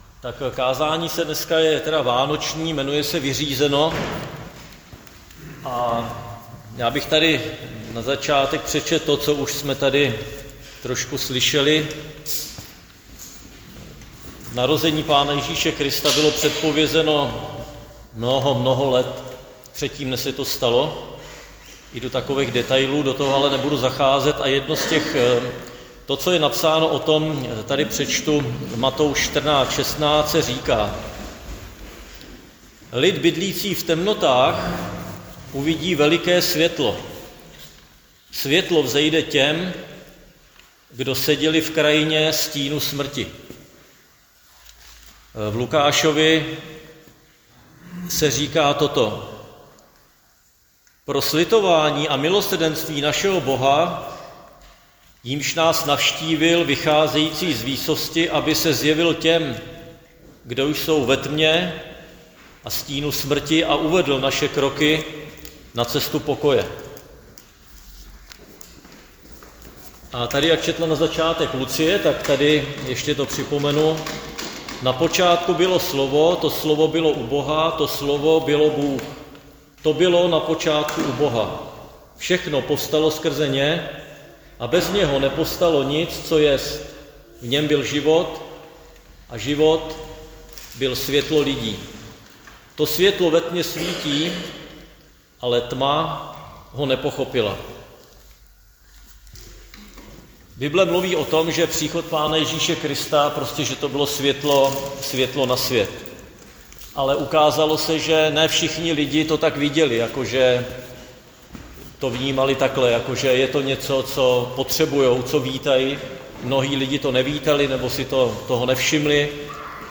Křesťanské společenství Jičín - Kázání